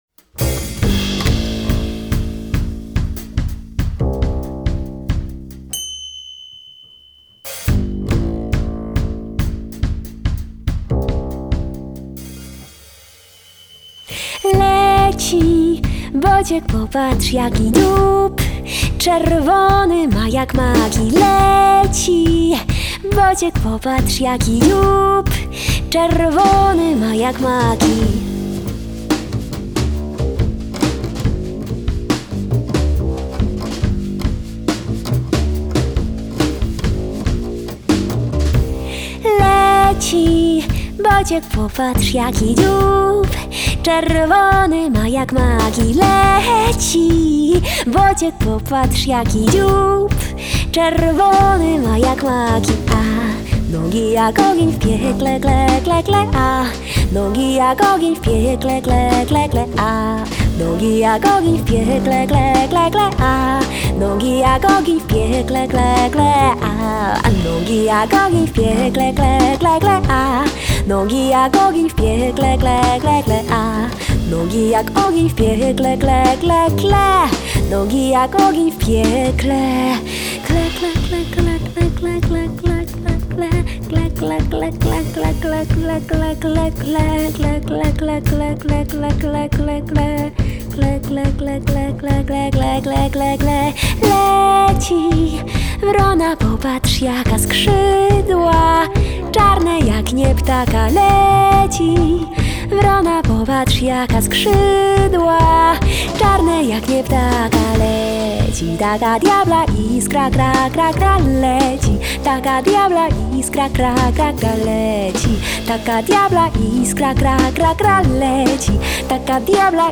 Genre: Vocal Jazz, Avant-Garde, Folk
klarnet, drumla, śpiew (clarinet, jaws harp, vocal)
kontrabas (double bass)